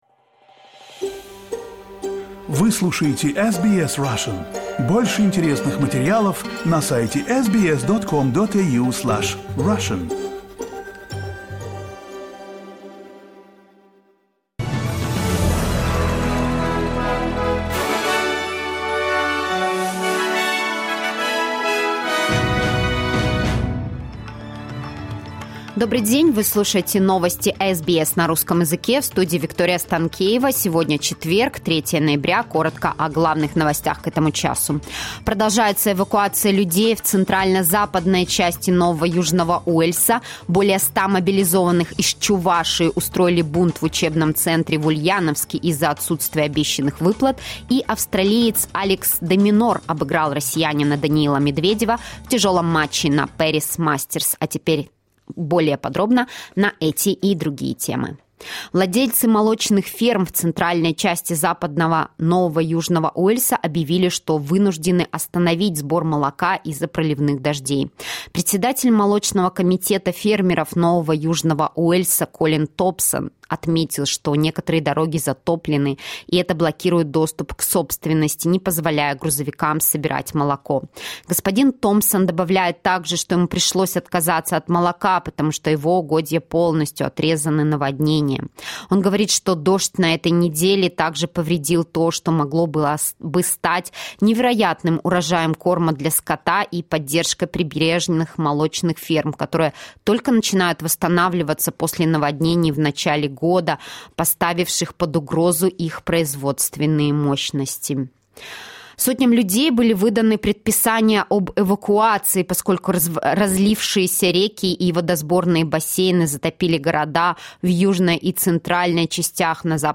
SBS news in Russian - 03.11.22